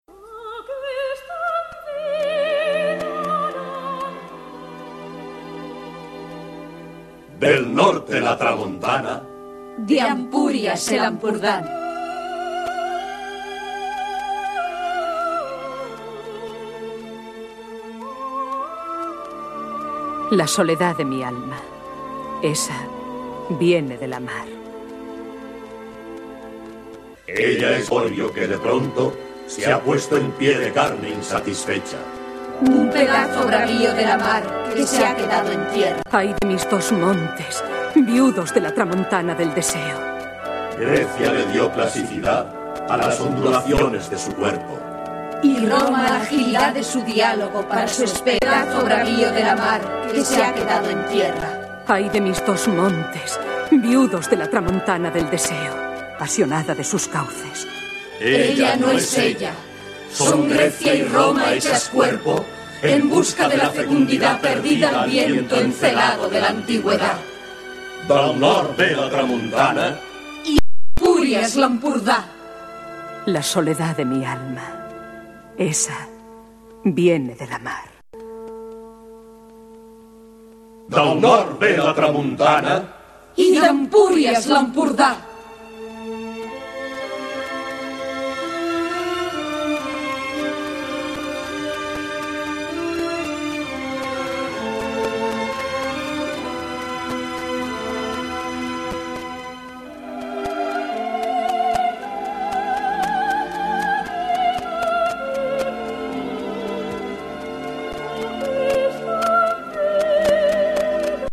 Radiodrama
Qualitat sonora una mica defectuosa.